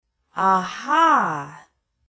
Звуки человека, аха
• Качество: высокое
Женщина с подозрением произносит аха